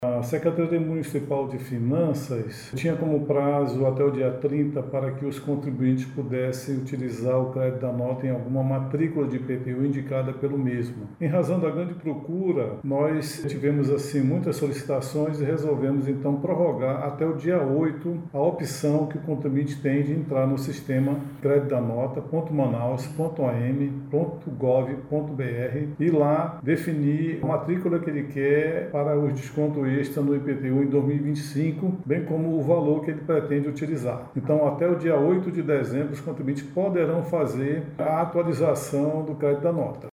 O subsecretário da Receita da Semef, Arminio Pontes, explica que o prazo foi prorrogado devido à alta procura pelo benefício.